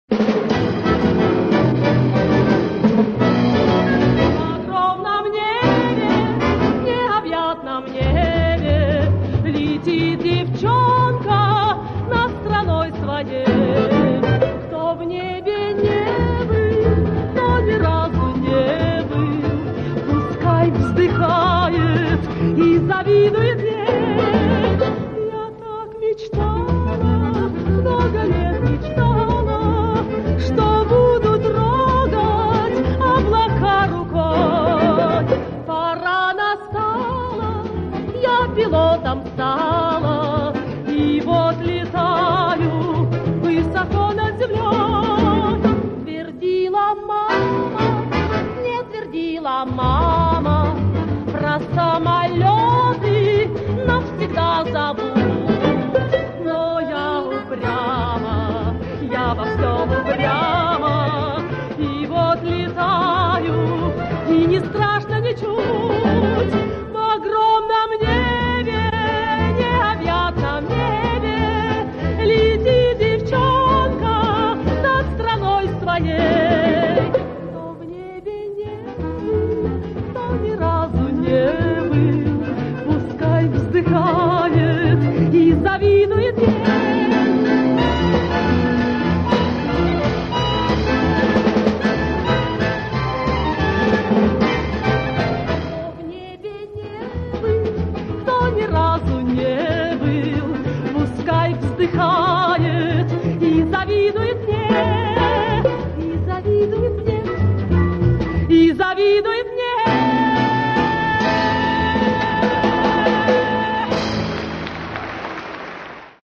Вот полностью из концерта
Похоже эти записи с радиоприемника 3-го класса.